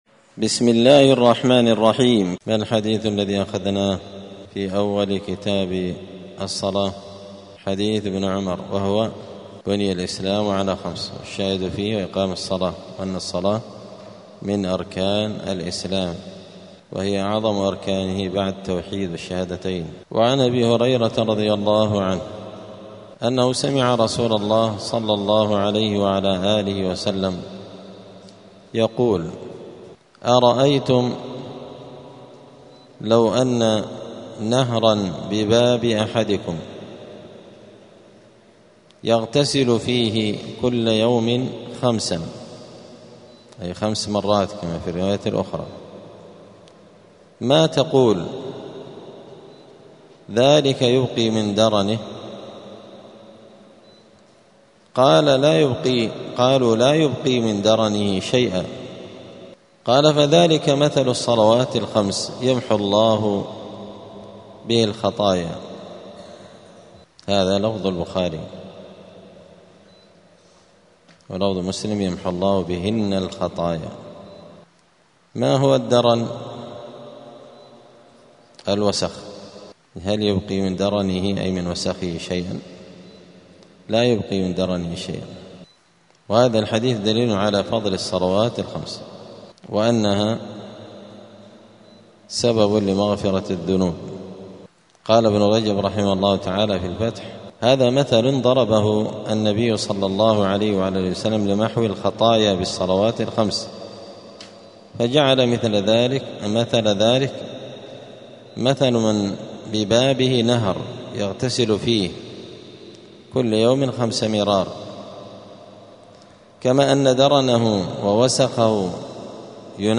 دار الحديث السلفية بمسجد الفرقان قشن المهرة اليمن
*الدرس الثاني والعشرون بعد المائة [122] {فضل الصلوات الخمس}*